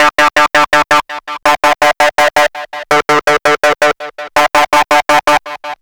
04 GaGaZaga 165 D.wav